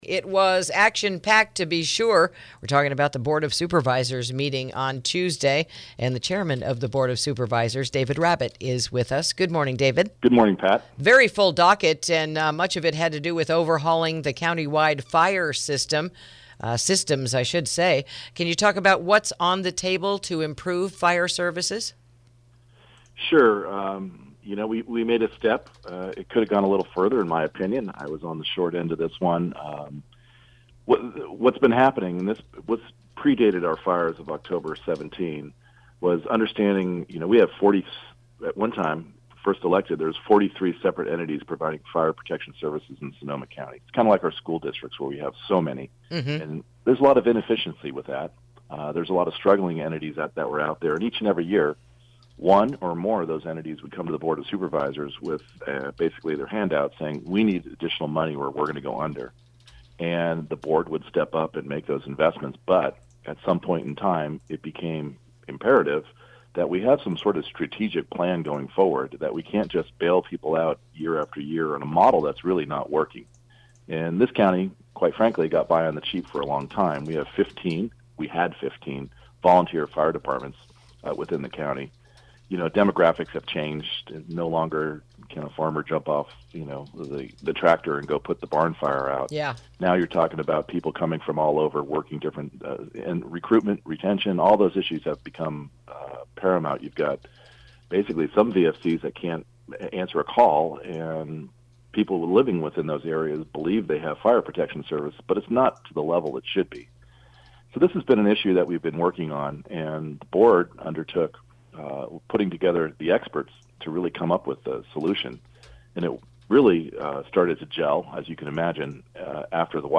INTERVIEW: Fire Protection the Main Topic at Last Nights Supervisor Meeting
Supervisor David Rabbitt recaps last night’s Board of Supervisors meeting, talking about what’s being done to improve fire services in the region, the funding issues that were addressed, the consolidation of certain fire districts to provide fire protection to every corner of Sonoma County, and where some of the funding is coming from: